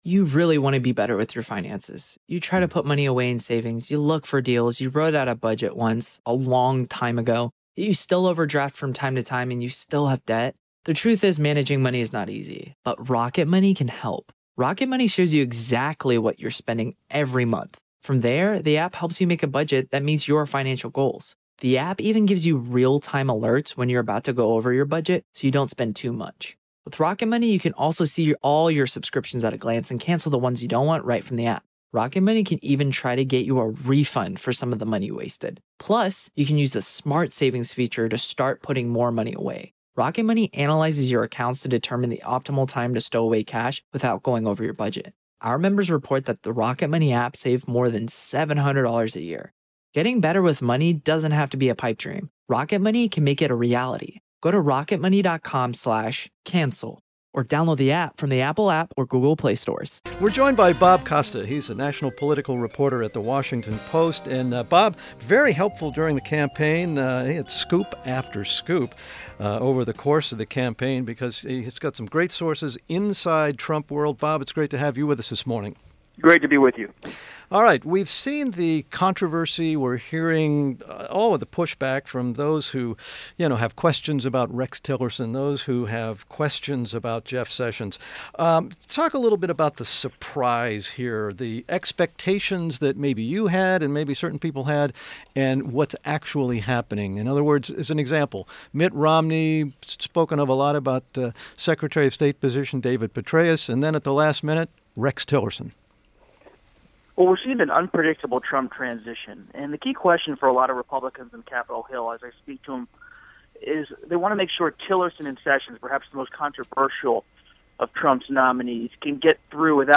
WMAL Interview - ROBERT COSTA - 12.19.16
INTERVIEW — ROBERT “BOB” COSTA – national political reporter at The Washington Post – discuss the latest Trump transition news